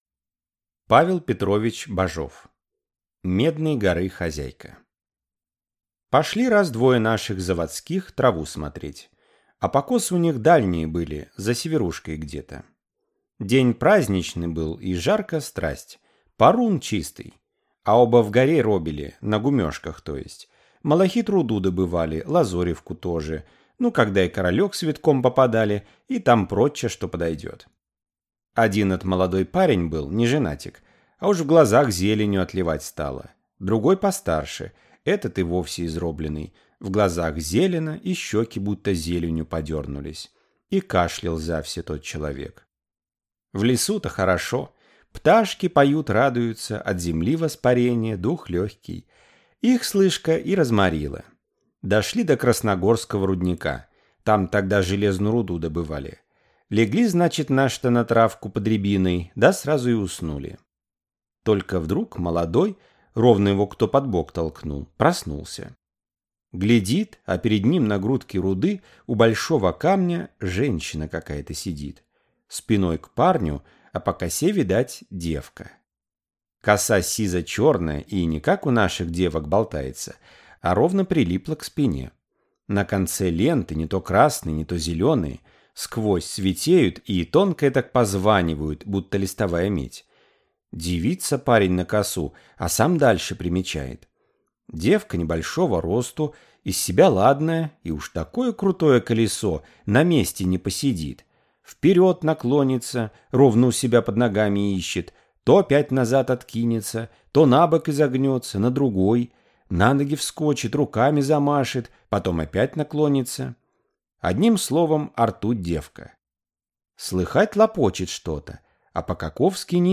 Аудиокнига Медной горы Хозяйка | Библиотека аудиокниг